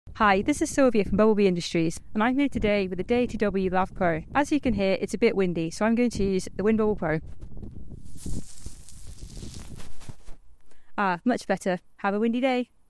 we put Mp3 Sound Effect In this video, we put the Deity W Lav Pro to the test outdoors, equipped with the Windbubble PRO to combat wind noise effectively. Whether you're out in nature or on location for interviews, this combo captures crisp, professional-grade audio while keeping wind noise at bay.